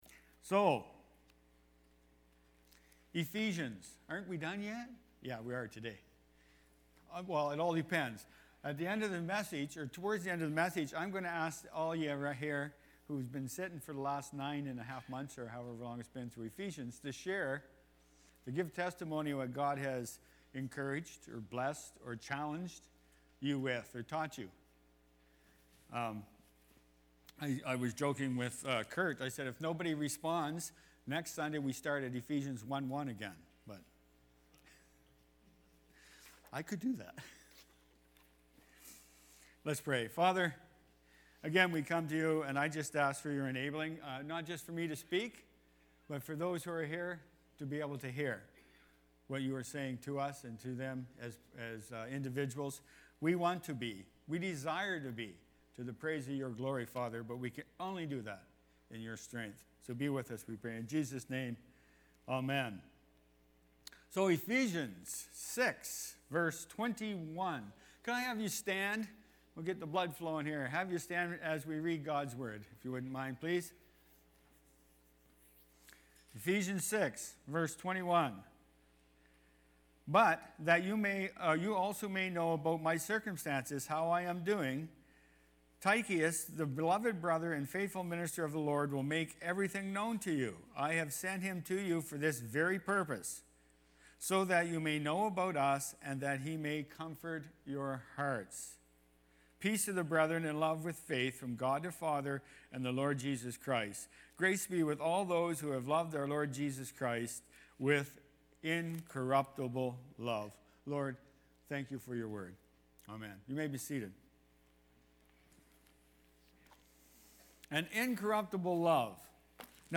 Sept-3-2023-sermon-audio.mp3